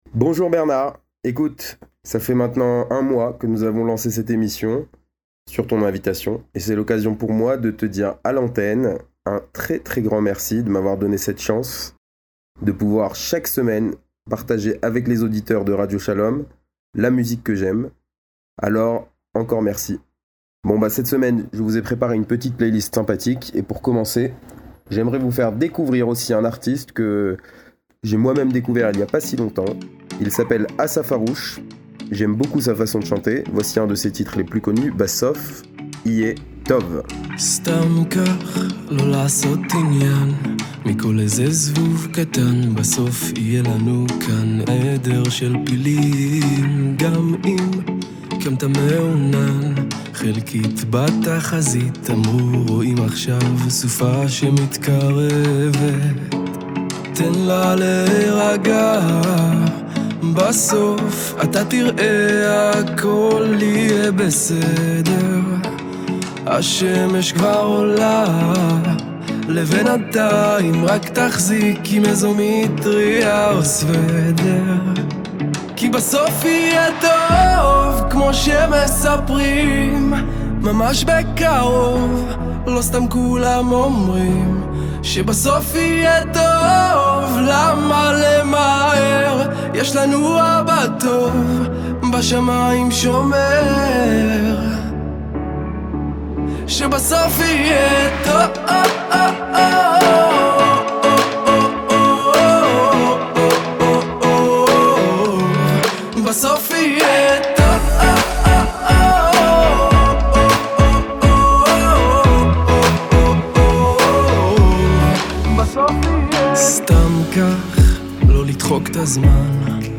le meilleur de la musique juive